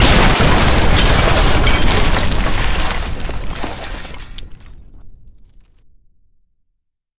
На этой странице собраны реалистичные звуки разрушения зданий: обвалы, взрывы, треск конструкций.
Звук рушащегося здания от взрыва